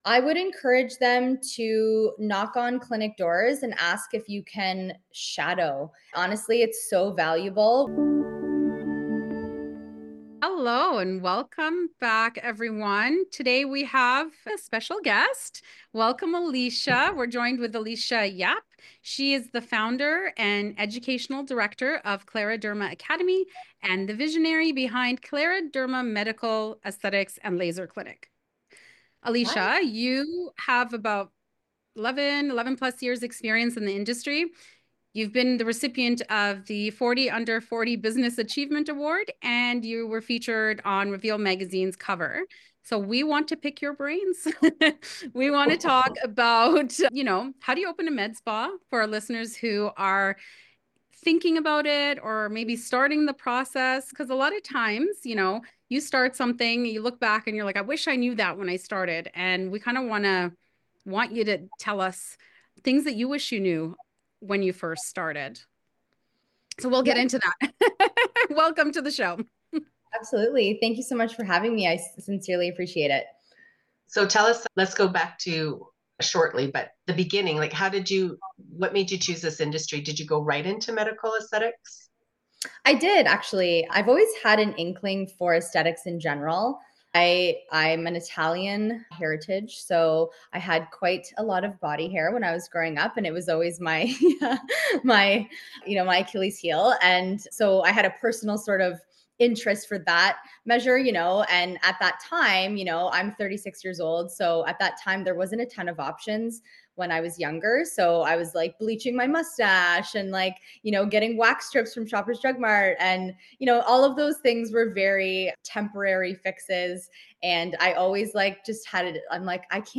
Welcome to Beauty Babble, your go-to podcast for all things aesthetics and spa!Hosted by industry experts, we're here to spill the tea on all the latest trends, game-changing techniques, resources for continued education and must-have products.And let's not forget the best part—exclusive interviews! Hear firsthand from industry pros and entrepreneurs as they share their experiences, tips, and strategies for success.